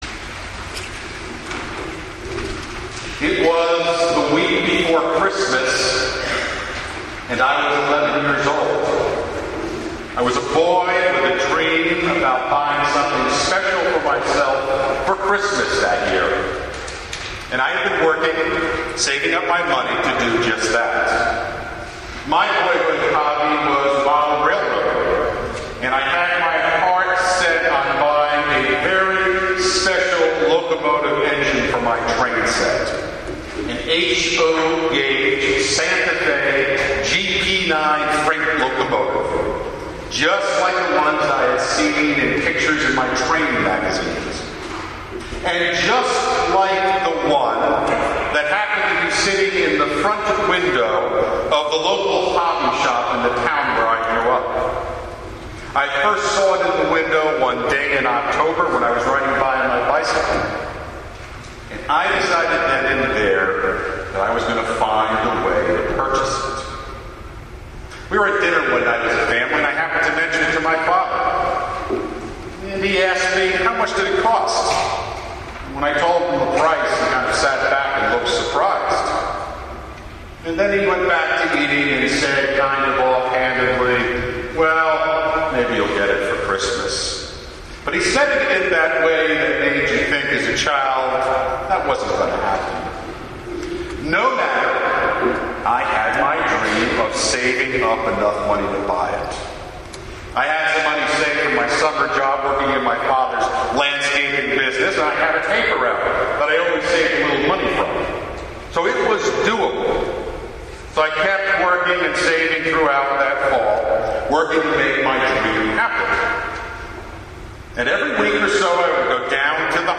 St. Paul's Episcopal Church | Sermon